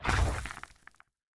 Minecraft Version Minecraft Version snapshot Latest Release | Latest Snapshot snapshot / assets / minecraft / sounds / mob / warden / step_3.ogg Compare With Compare With Latest Release | Latest Snapshot
step_3.ogg